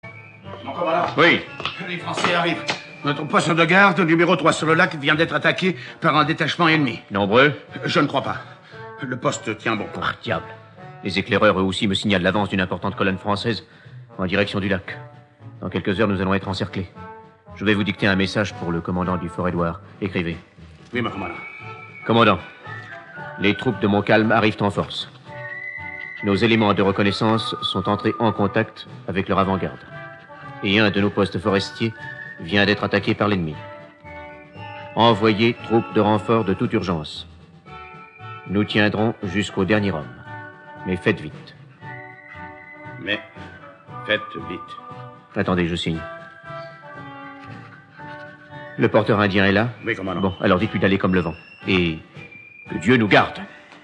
Diffusion distribution ebook et livre audio - Catalogue livres numériques
Rééedition de la version de 1955